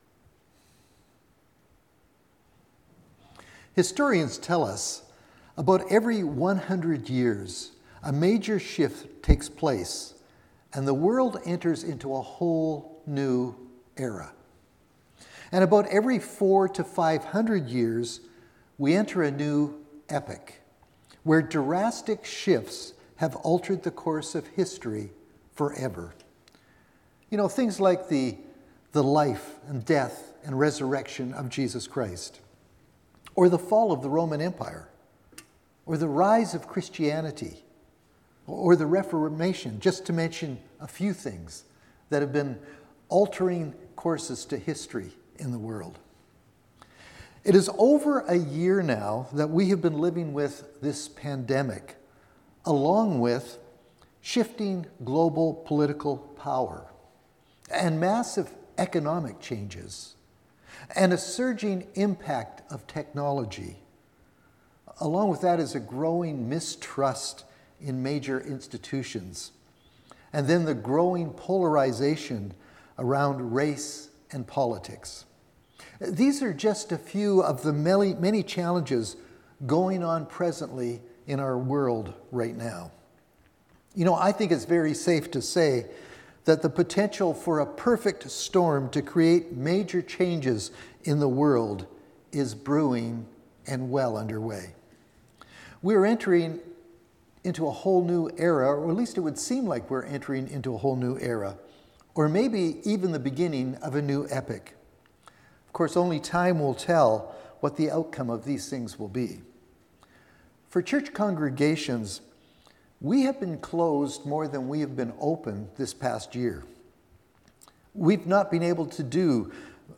Sermons | Cumberland Community Church
We will also be celebrating Communion.